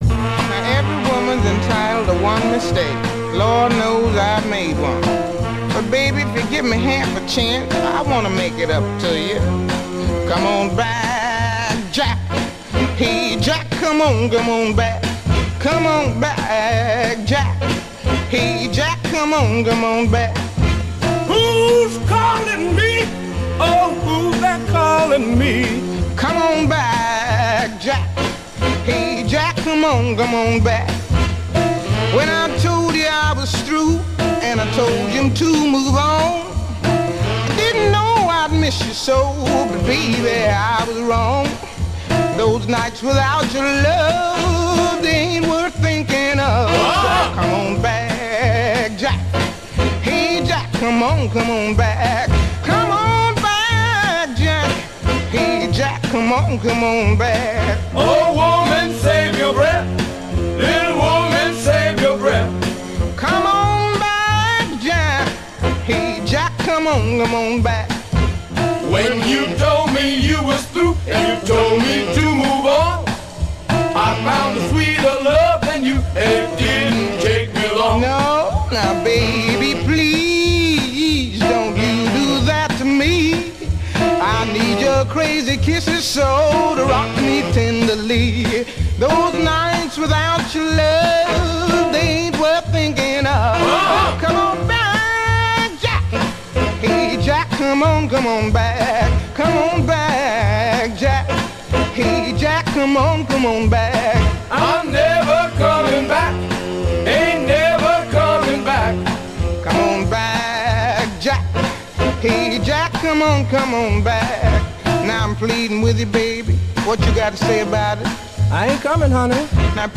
En voilà quelques exemples piochés dans la country, le rock'n'roll, la soul ou le doo-wop, histoire de voir comment des musiciens peuvent se faire écho en se plagiant gentiment...